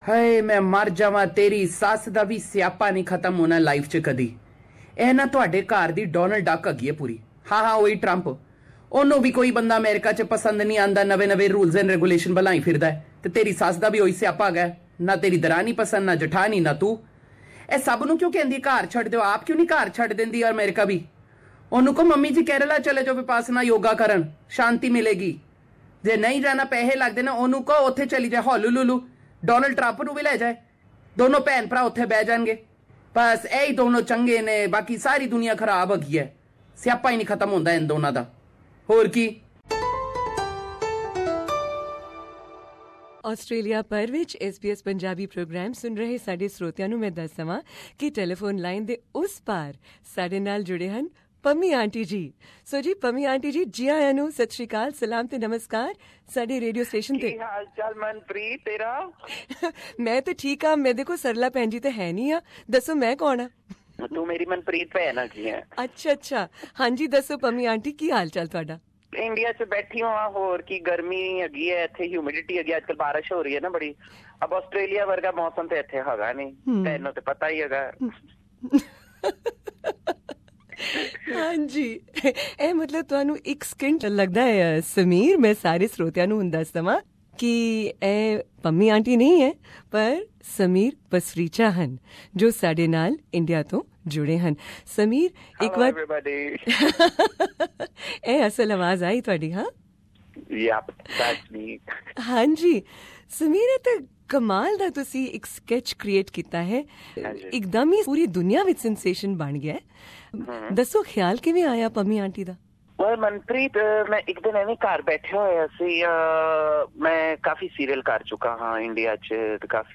Actor Ssumier Pasricha aka Pammi Aunty Source: SSumier S Pasricha Hear this candid and laughter filled interview with Ssumier Pasricha aka Pammi Aunty, in which he shares some of his recent comedy sketches as well.